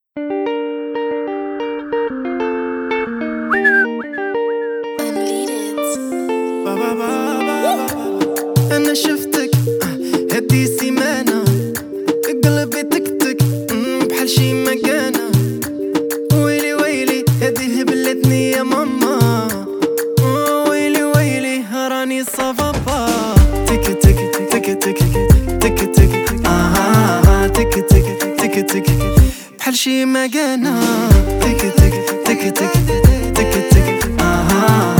Жанр: Поп
# Arabic Pop